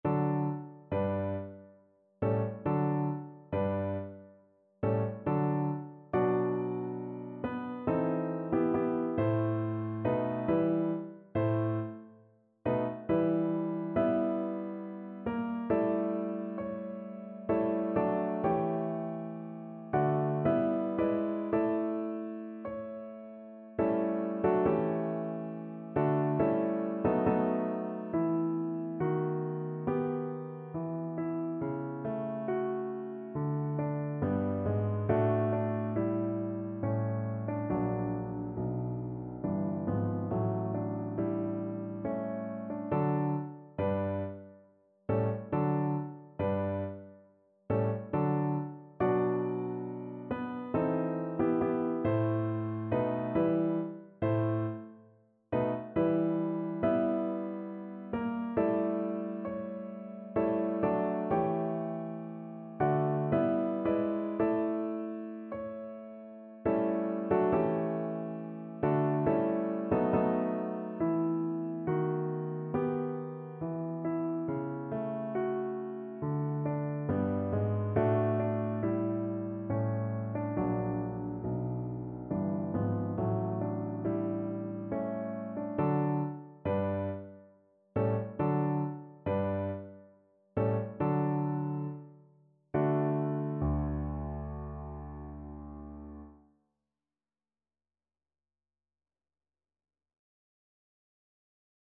3/4 (View more 3/4 Music)
Larghetto =69